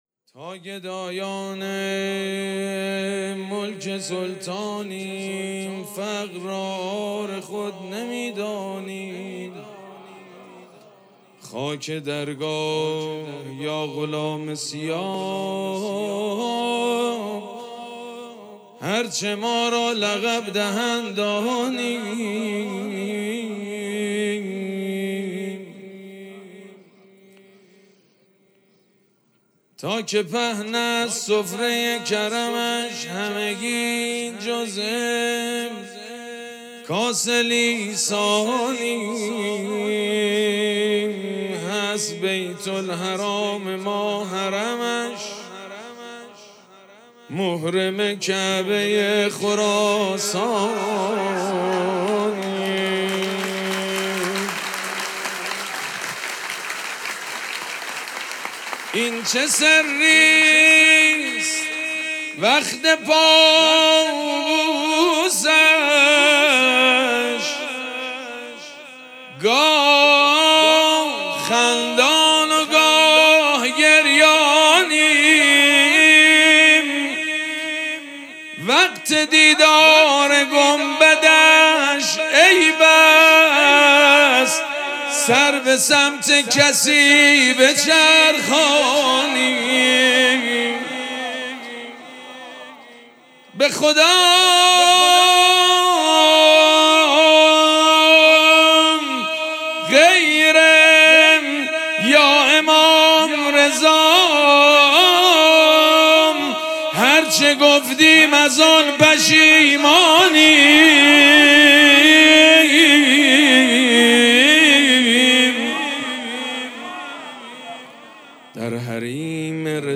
مراسم جشن ولادت امام رضا علیه‌السّلام پنجشنبه ۱۸ اردیبهشت ماه ۱۴۰۴ | ۱۰ ذی‌القعده ۱۴۴۶ حسینیه ریحانه الحسین سلام الله علیها
سبک اثــر مدح